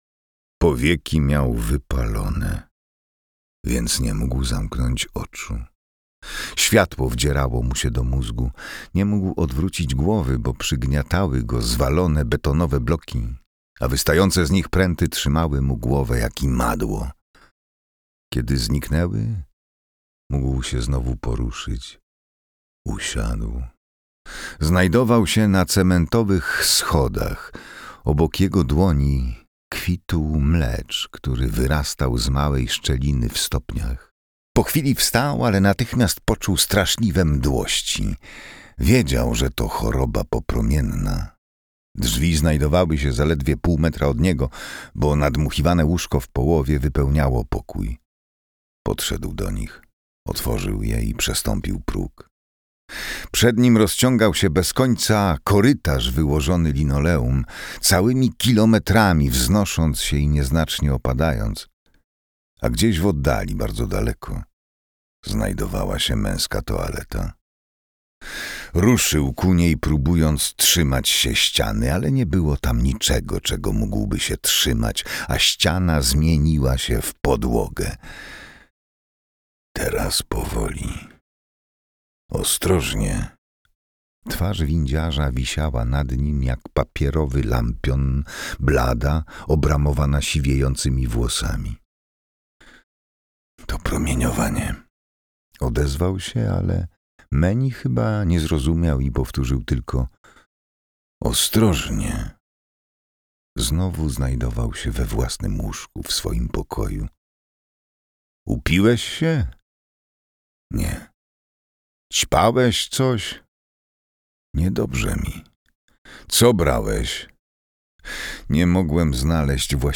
Jesteśmy snem - Ursula K. Le Guin - audiobook